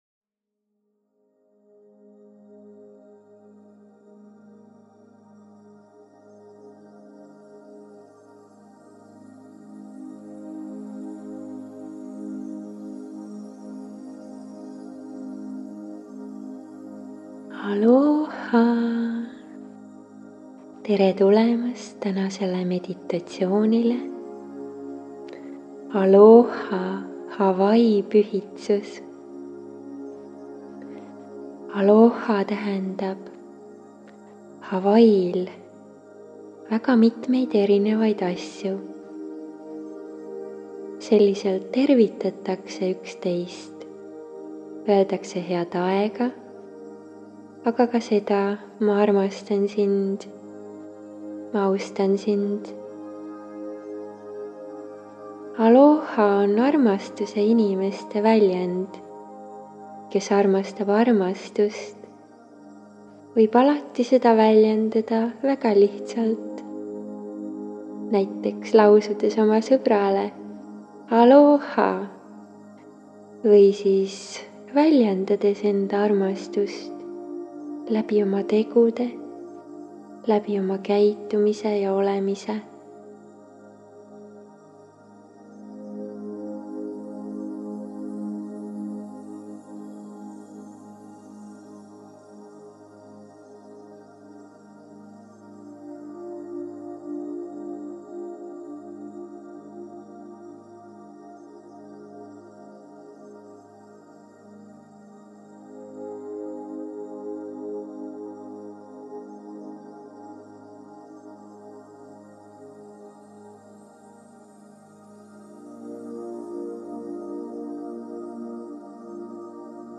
MEDITATSIOONI ALOHA HAWAII PÜHITSUS salvestatud aastal 2020